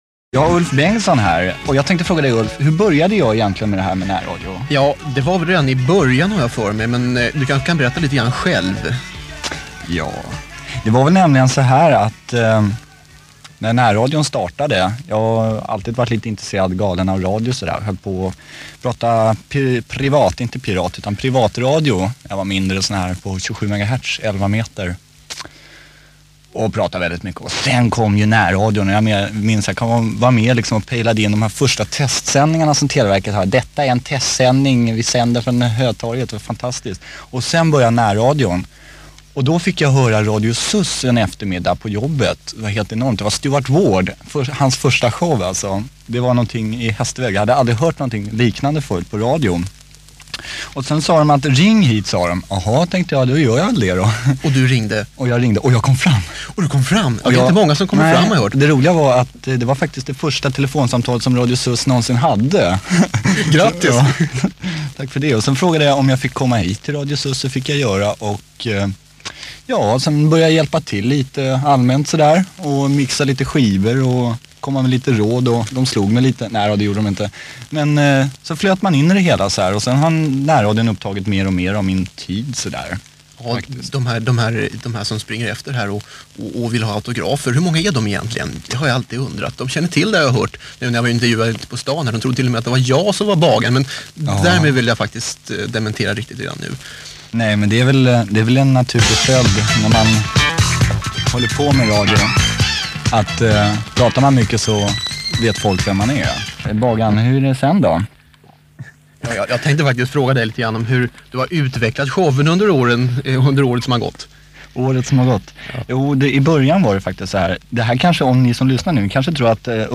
Svajig kassettradioinspelning. 1 tim 1 min.